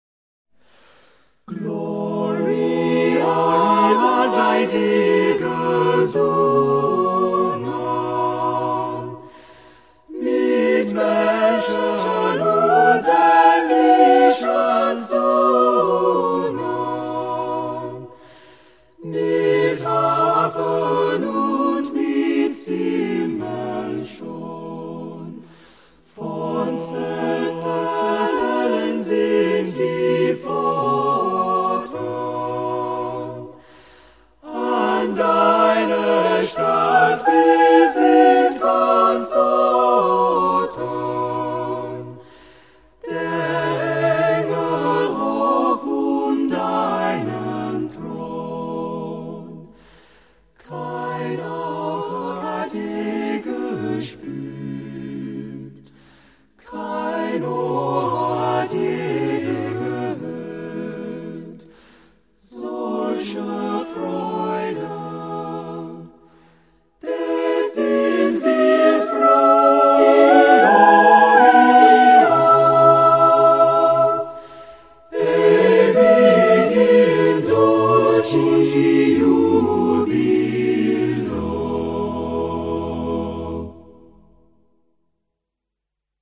choral final 140.mp3